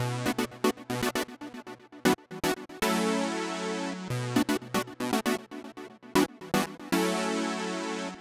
11 Chord Synth PT2.wav